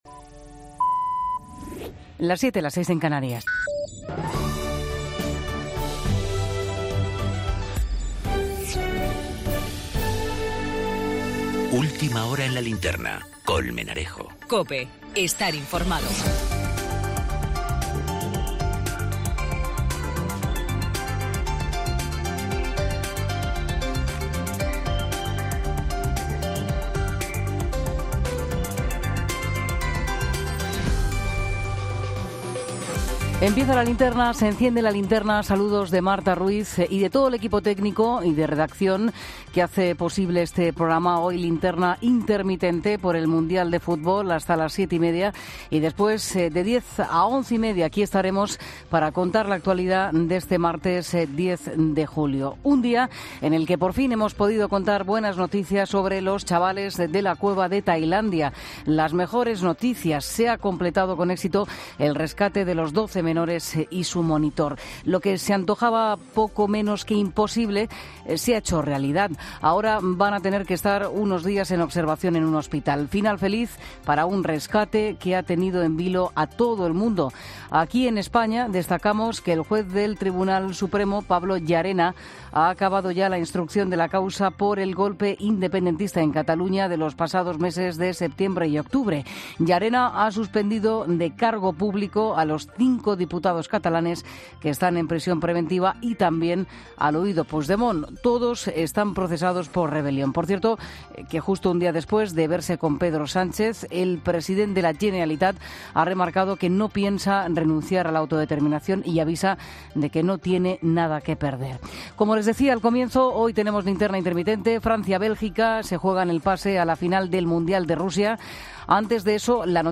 Los oyentes